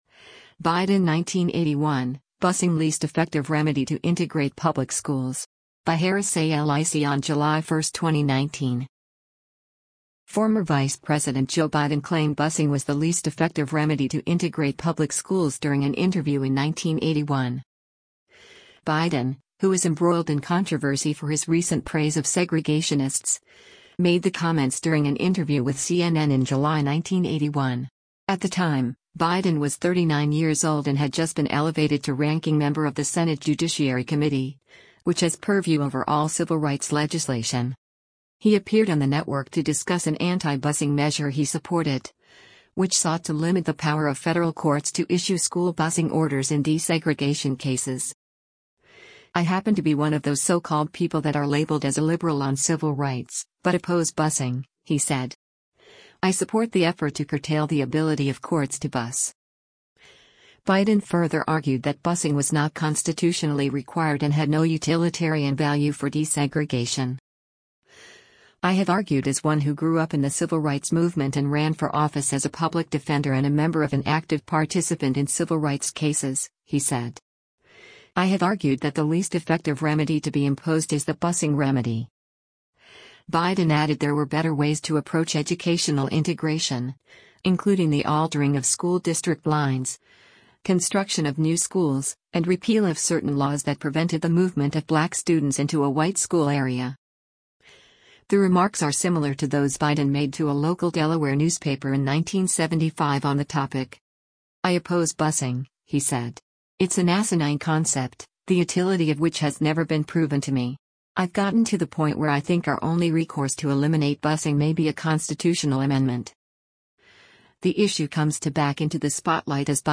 Former Vice President Joe Biden claimed busing was the least “effective remedy” to integrate public schools during an interview in 1981.